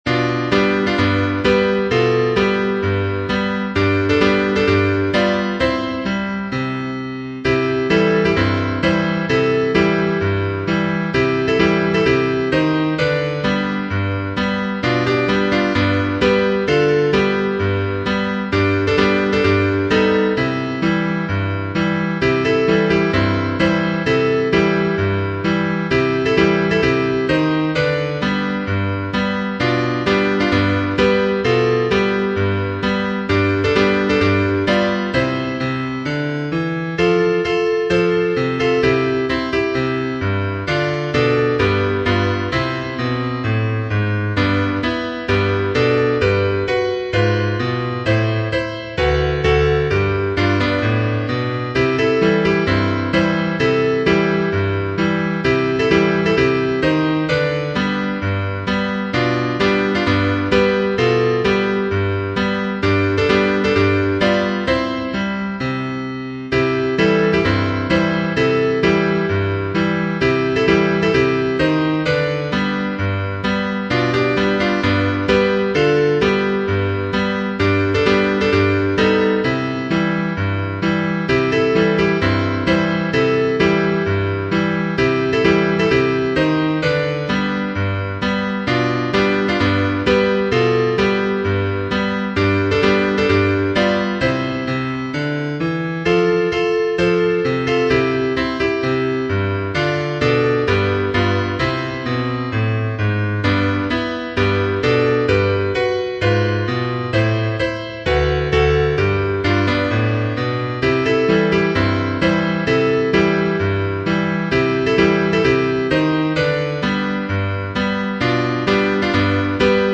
ハンドベル